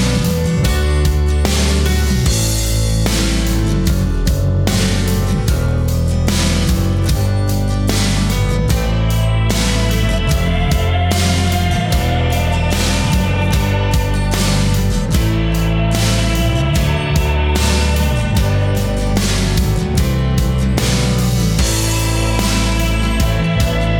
no Backing Vocals Indie / Alternative 4:17 Buy £1.50